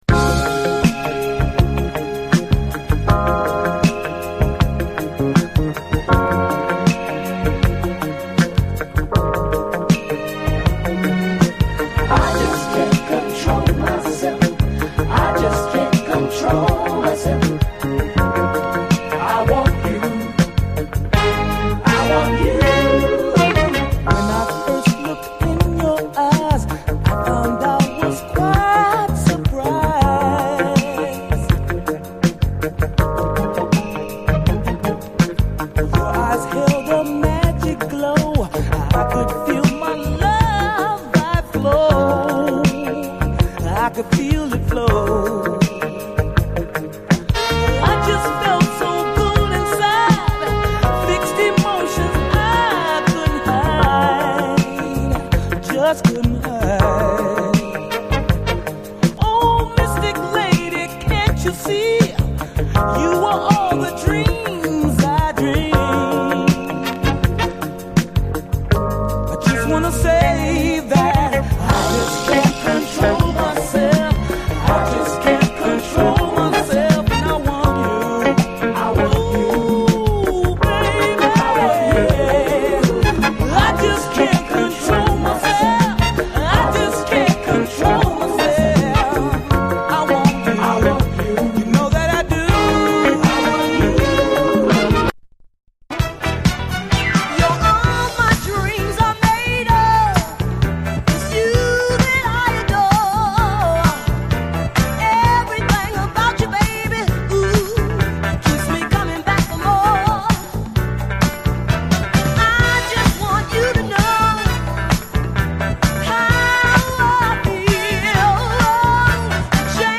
SOUL, 70's～ SOUL
夕暮れメロウ・フリーソウル
こみ上げるディスコ・ブギー
デトロイトの10人組ソウル〜ファンク・バンド！隠れたメロウ・フリーソウル